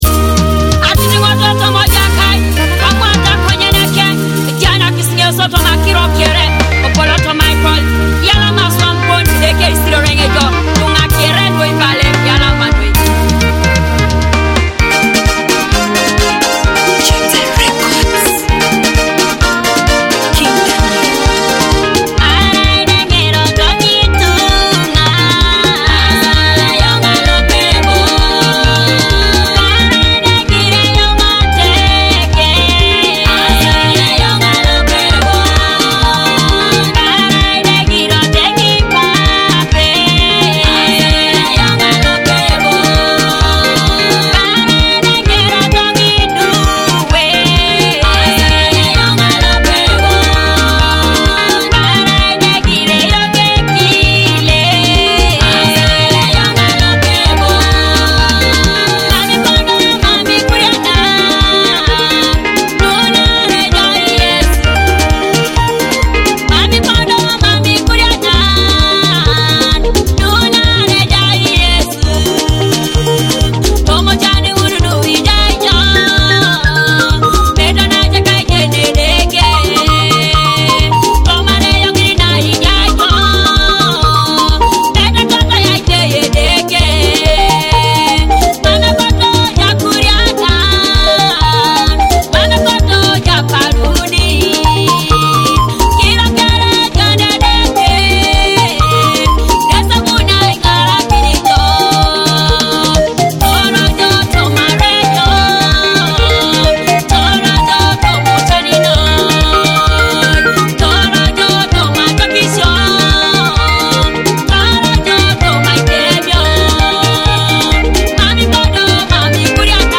Uplifting gospel hit